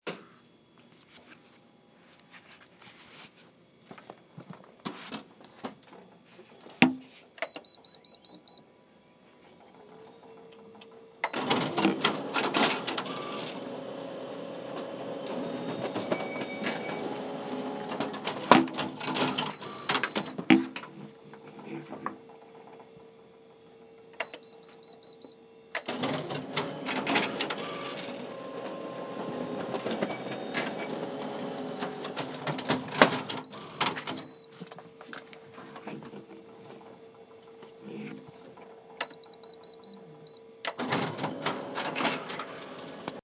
In the Axinn Library. Copying a play.
Copy-Machine.mp3